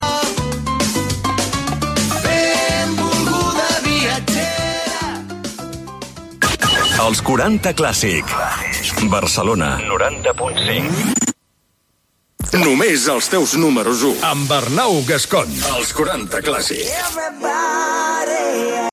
Tema musical, indicatiu de l'emissora a Barcelona